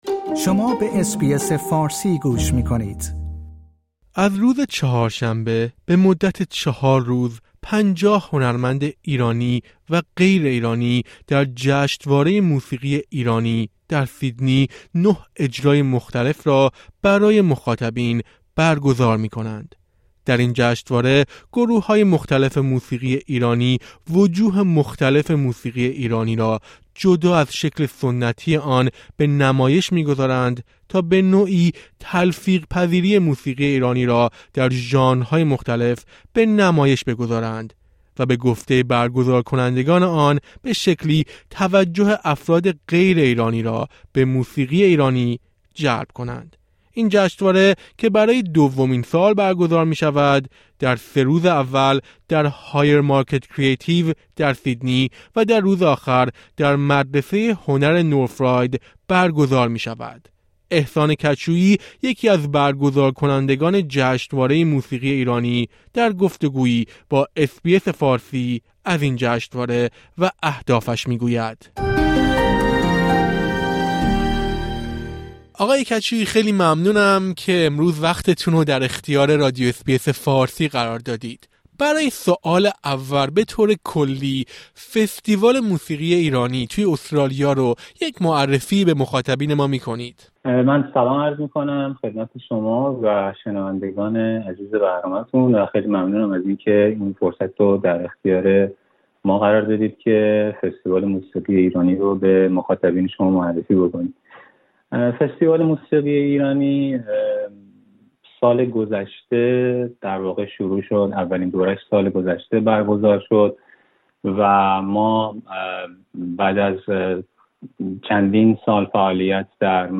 در گفت‌وگو با اس‌بی‌اس فارسی